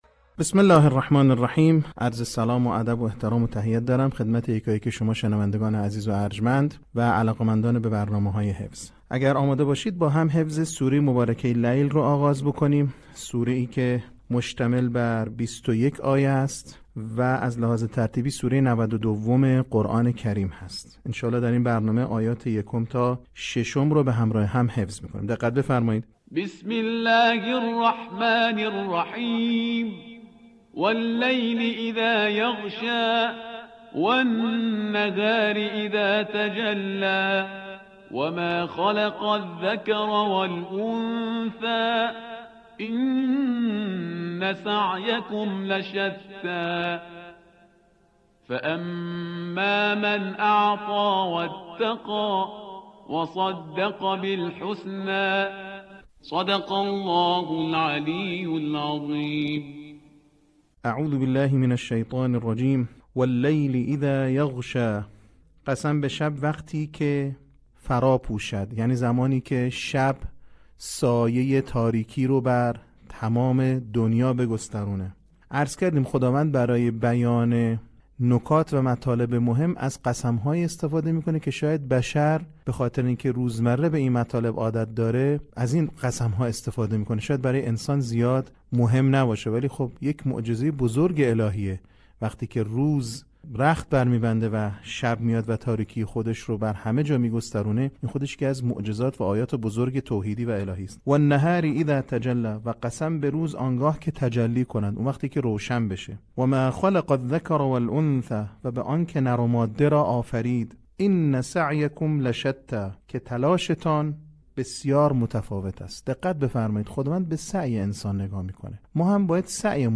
صوت | آموزش حفظ سوره لیل